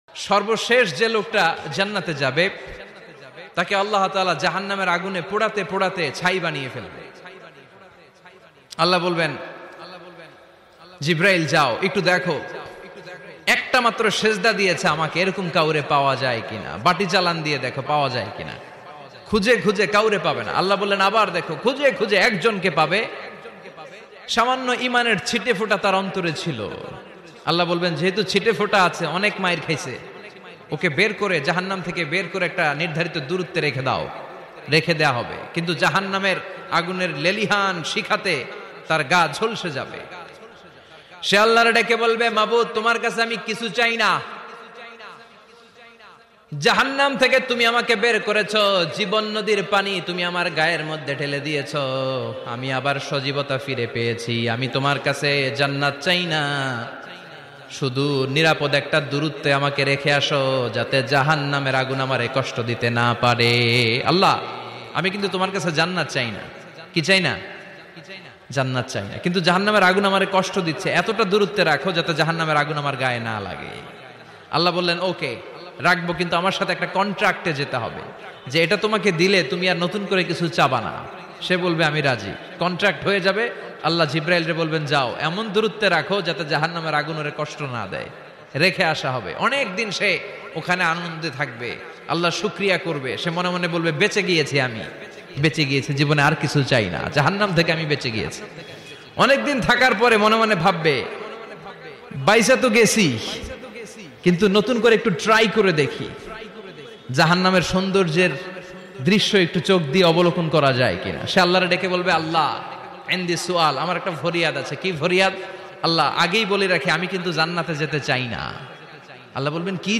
Waz